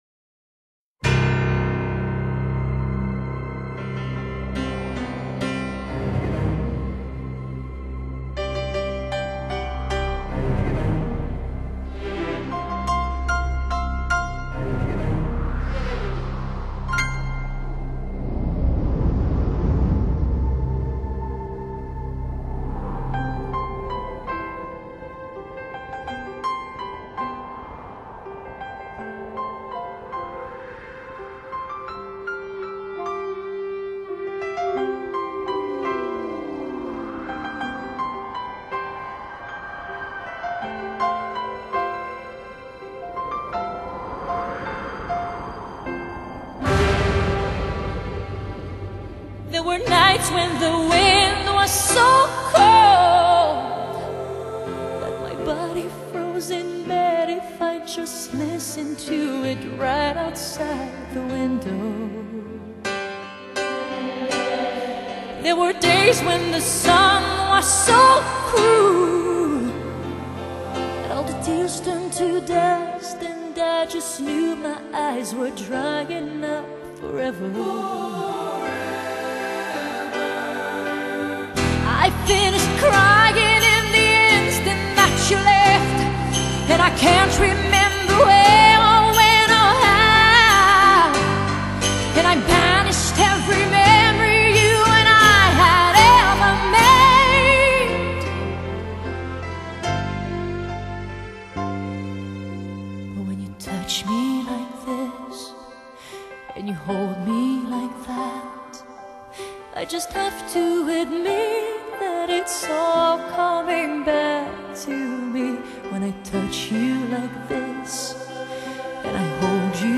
Pop, ballads